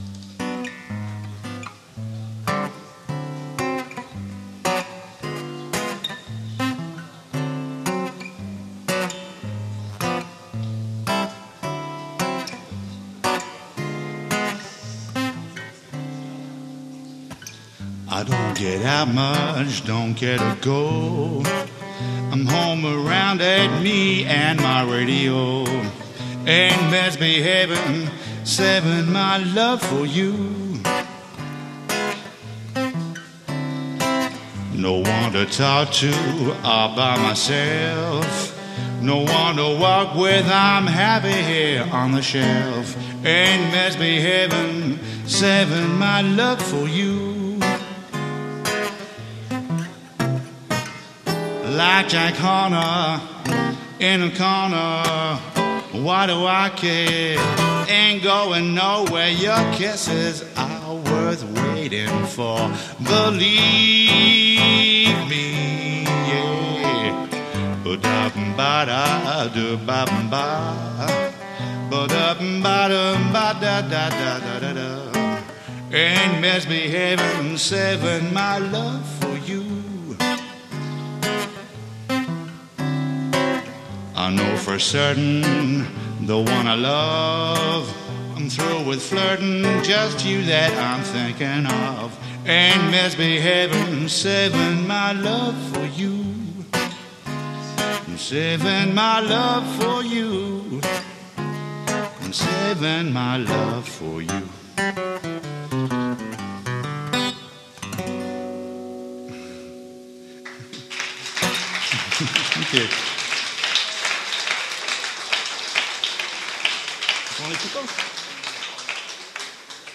"live" in Cologne